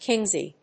/ˈkɪnzi(米国英語), ˈkɪnzi:(英国英語)/